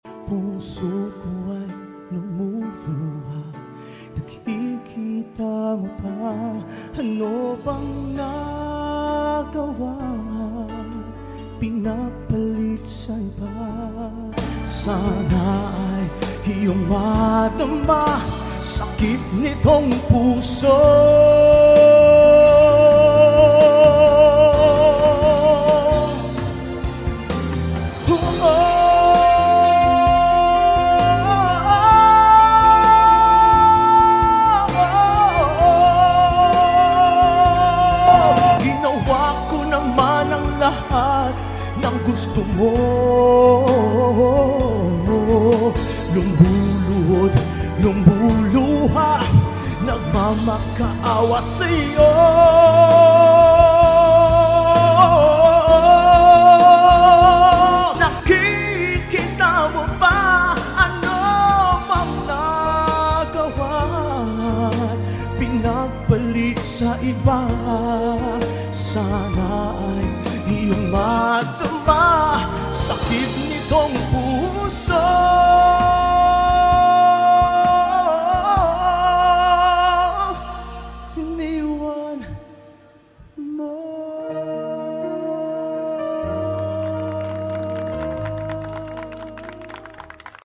perform my original song in Lapu-Lapu City during the Kadaugan sa Mactan celebration